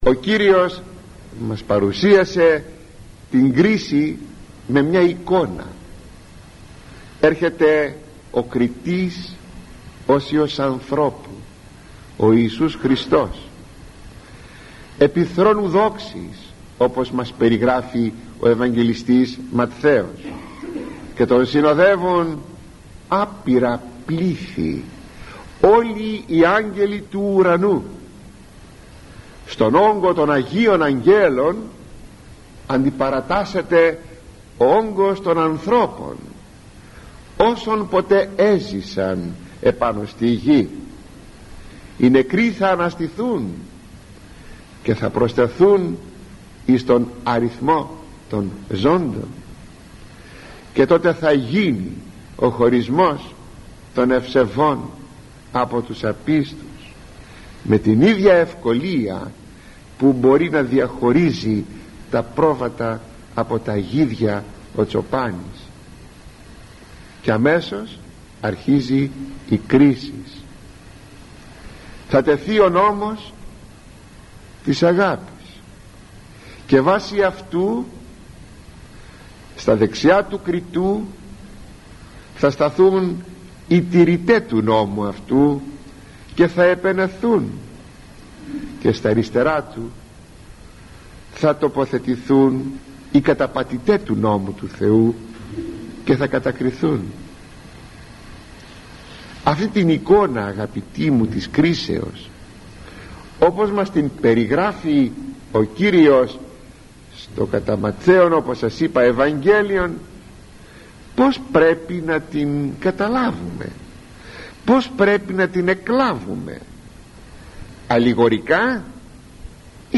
ηχογραφημένη ομιλία